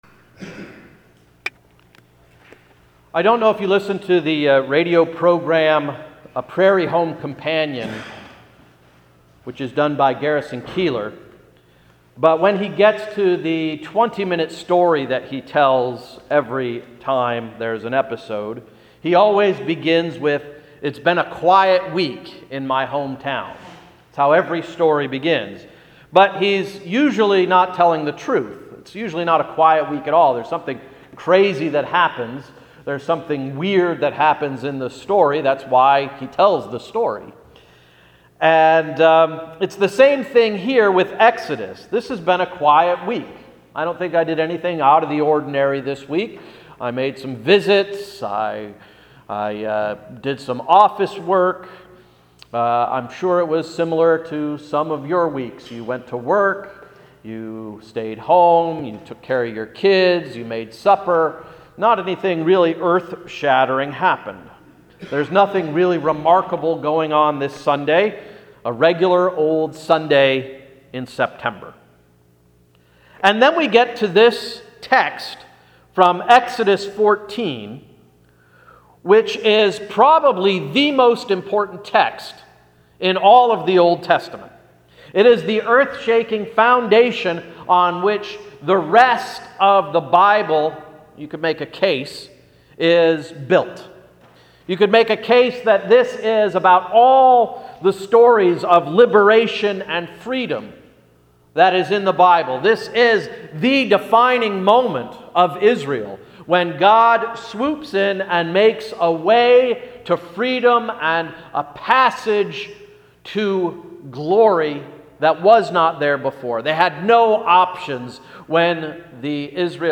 Sermon of September 14, 2014–“Water Wings”